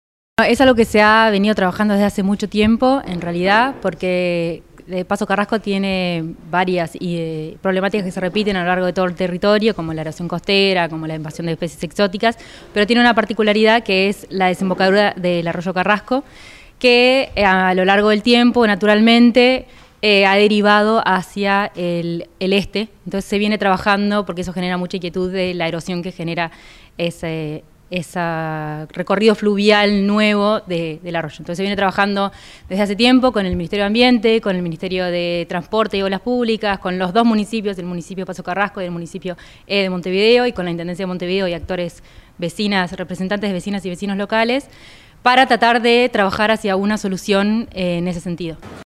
En la Ludoteca del parque Roosevelt, se llevó a cabo la presentación del Programa de Restauración Ecológica para la costa de Canelones.